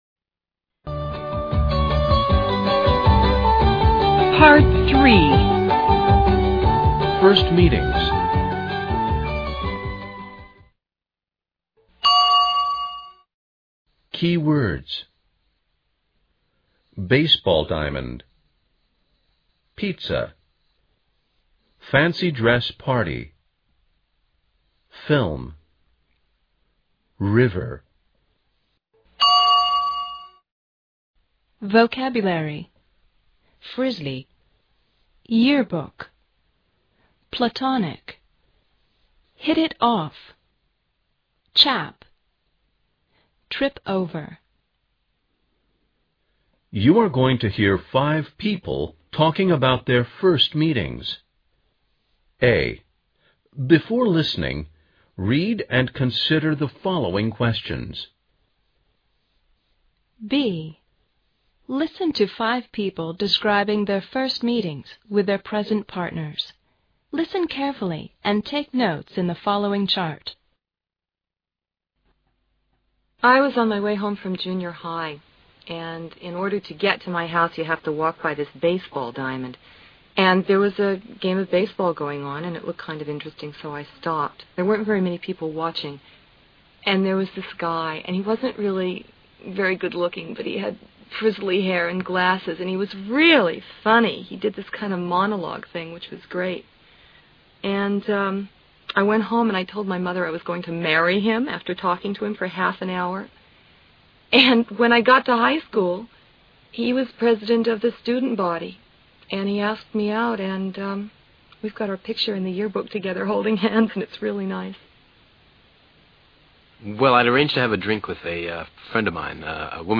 You're going to hear five people talking about their first meetings.